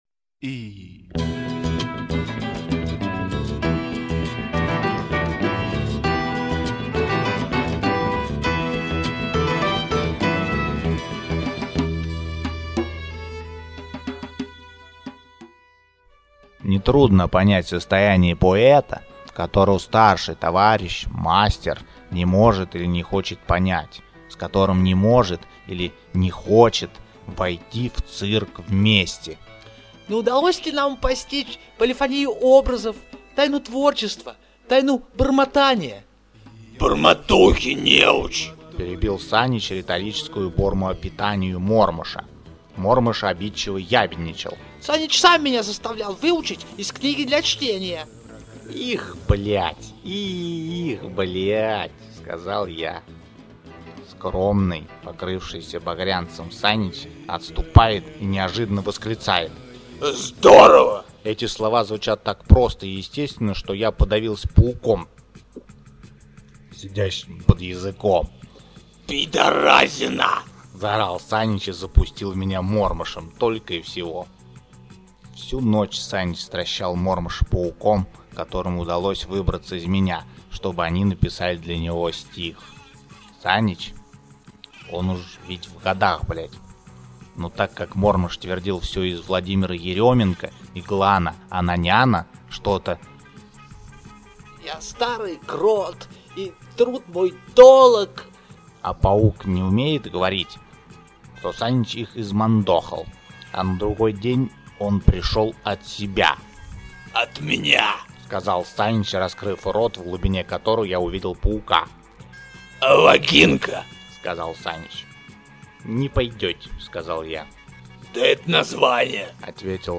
Аудиокниги